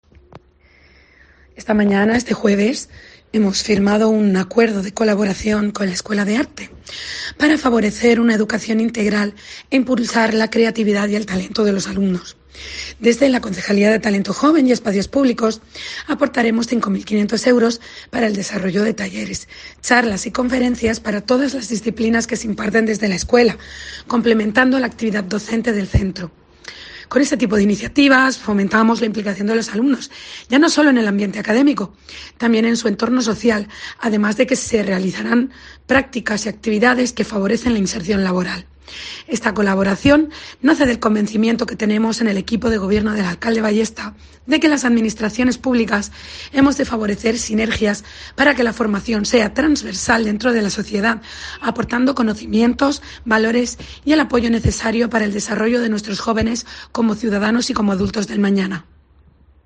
Sofía López Briones, concejala de Talento Joven